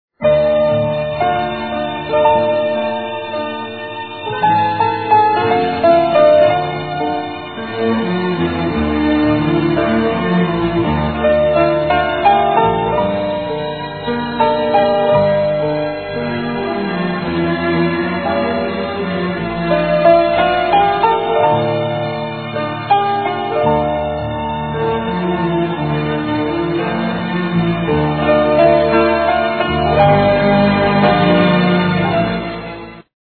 ملودی